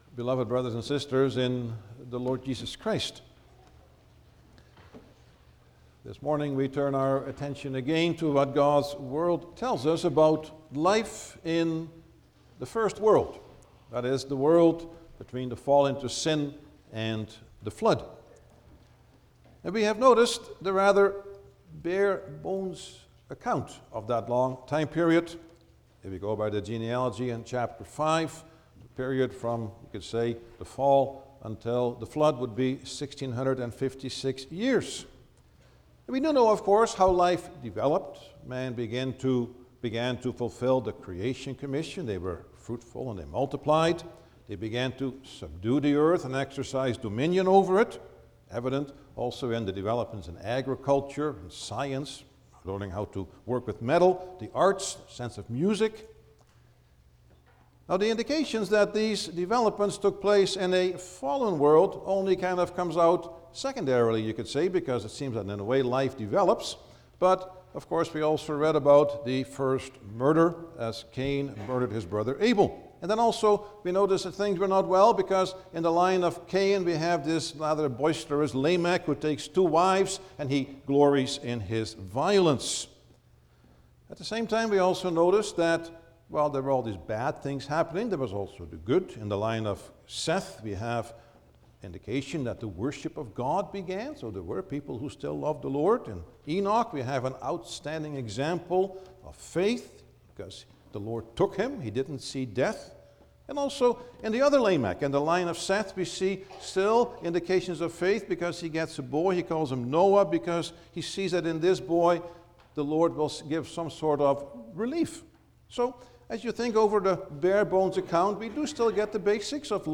Service Type: Sunday morning
10-Sermon.mp3